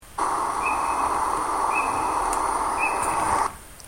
Krickente
krickente.mp3